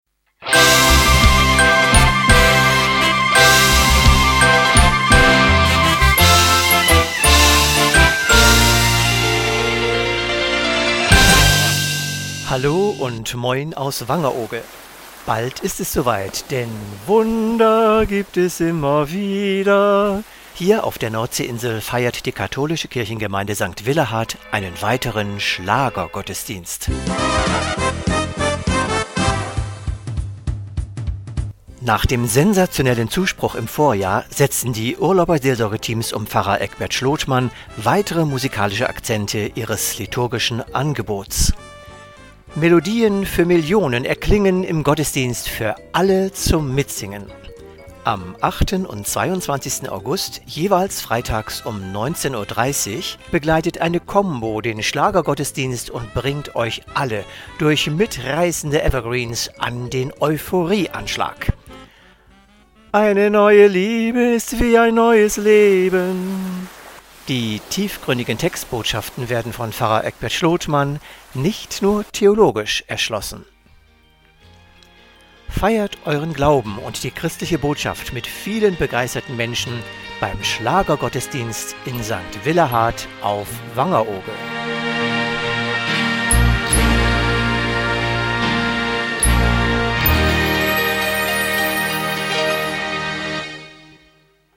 Schlagergottesdienst
Mit Evergreens zum Mitsingen
Die mitreißende Kraft der Schlagermelodien bringt alle Generationen an den Euphorie-Anschlag.